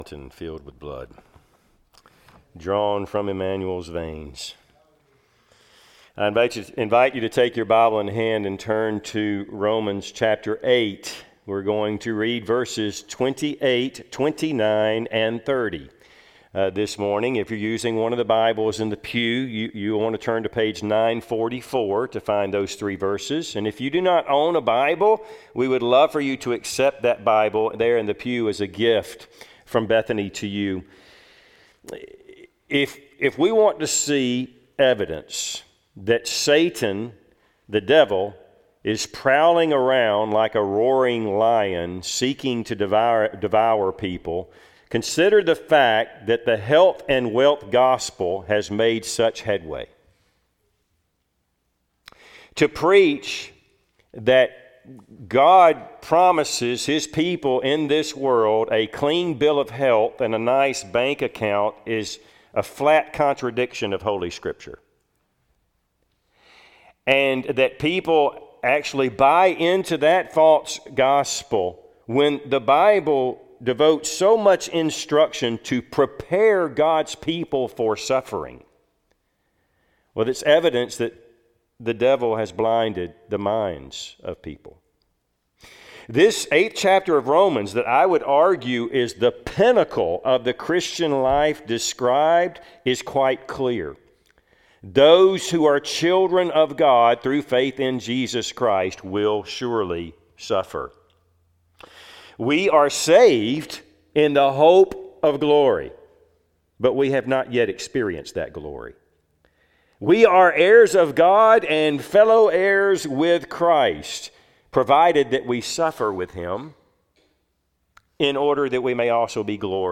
Service Type: Sunday AM Topics: Comfort , God's providence , Suffering